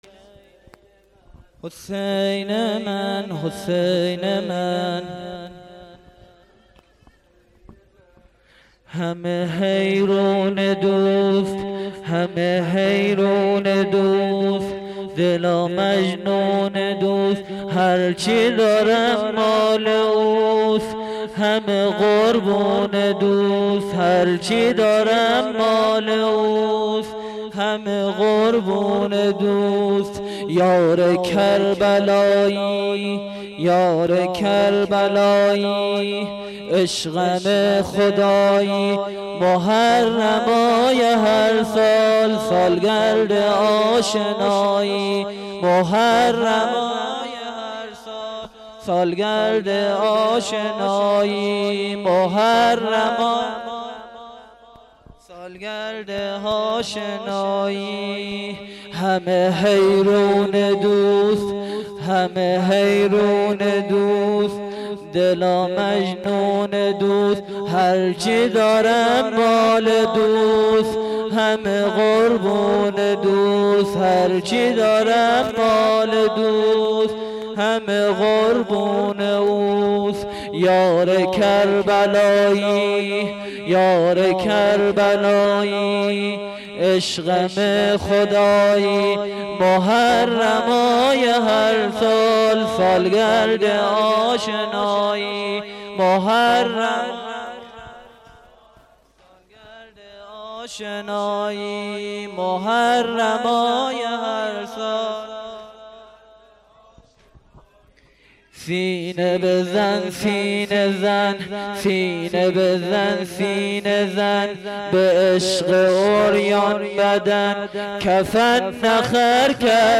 زمینه شب 11
شب 11 محرم 93 هیآت ثارالله (6).mp3
شب-11-محرم-93-هیآت-ثارالله-6.mp3